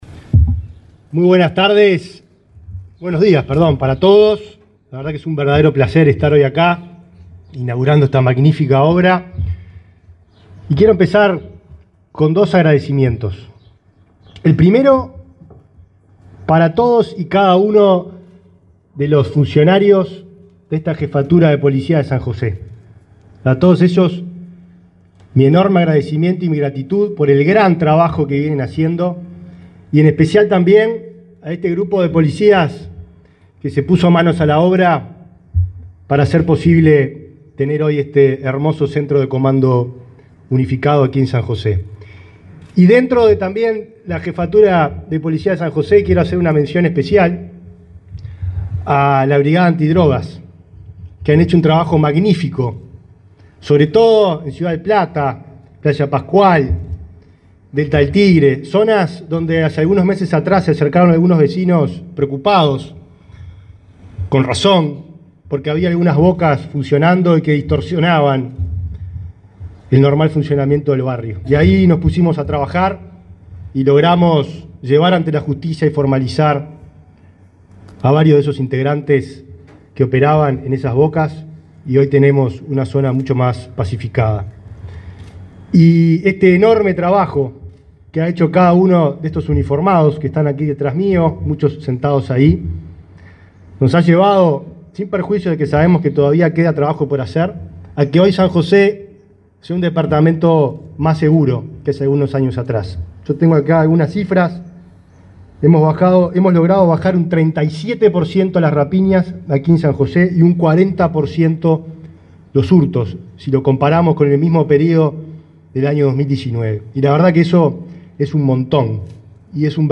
Palabras del ministro de Interior, Nicolás Martinelli
Este miércoles 13, el ministro de Interior, Nicolás Martinelli, participó en la inauguración del Centro de Comando Unificado en la Jefatura de Policía